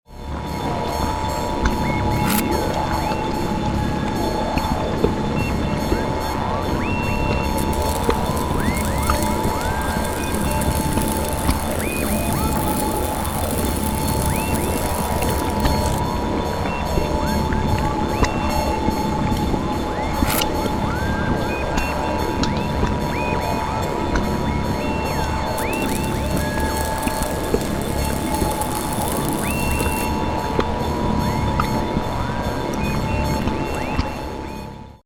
Звуки со смехом злого ученого, маньяка и его лаборатория для монтажа видео в mp3 формате.
laborat-uchenogo.mp3